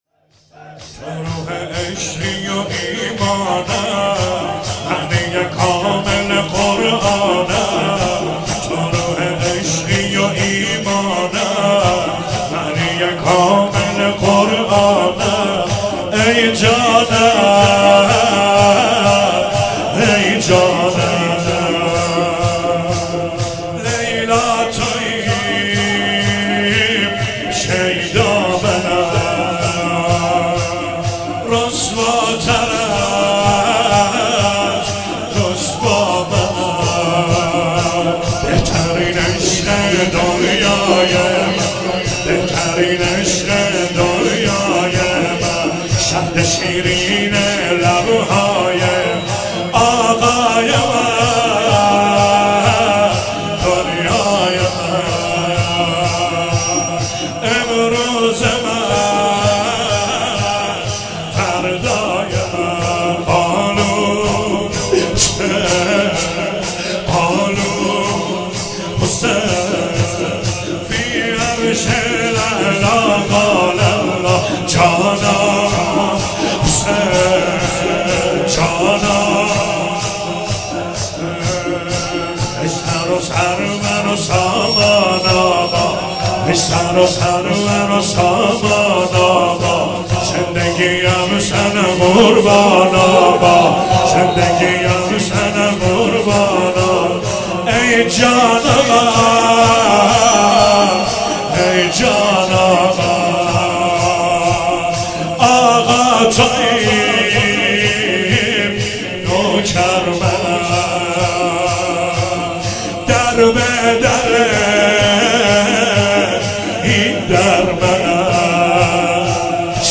هفتگی 23 آذر96 - شور - تو روح عشقیو ایمانم